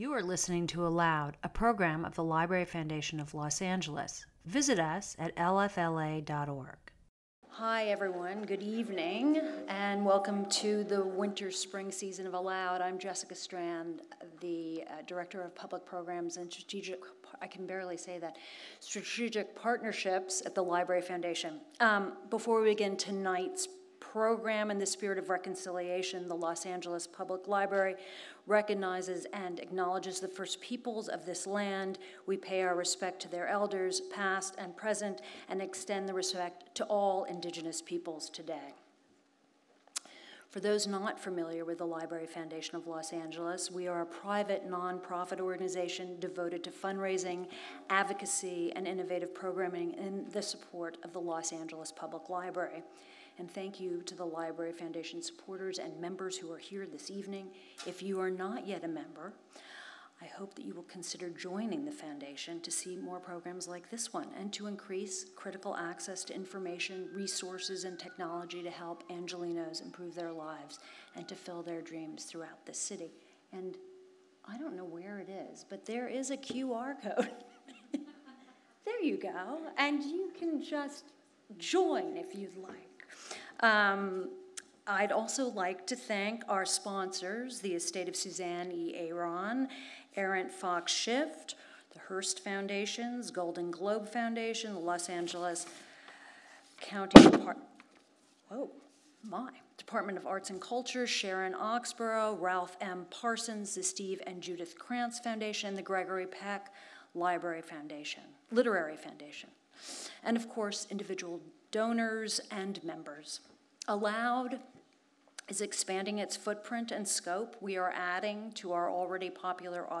Sheila Heti In Conversation With Michelle Tea